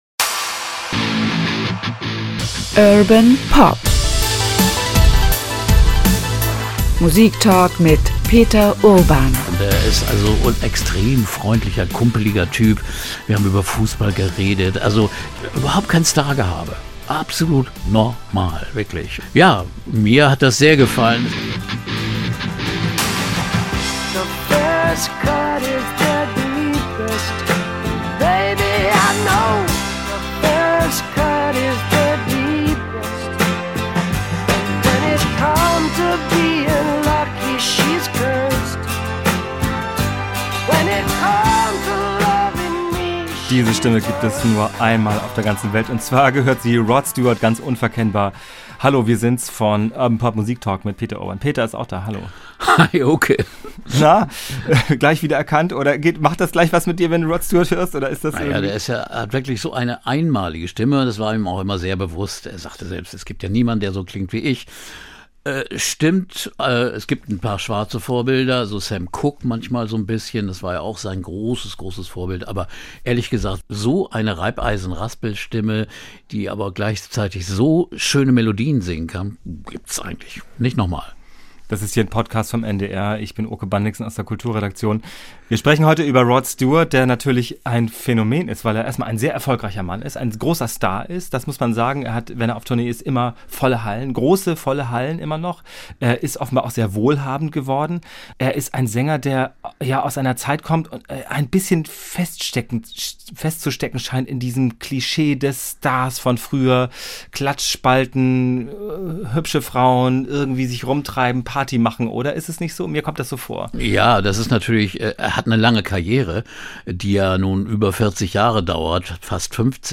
Peter Urban erzählt von persönlichen Treffen mit Rod Stewart bei Konzerten, im NDR Hörfunk Studio und von einer besonderen Aufzeichnung in Hamburg. Im Gespräch